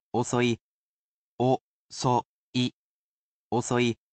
Though he’s a robot, he’s quite skilled at speaking human language. He’s lovely with tones, as well, and he will read each mora so you can spell it properly in kana.